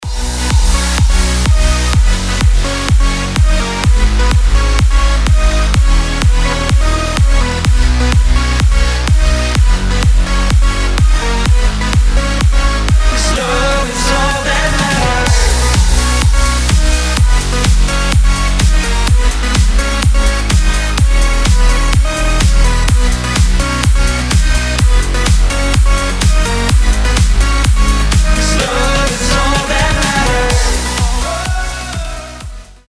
dance
Electronic
progressive house
house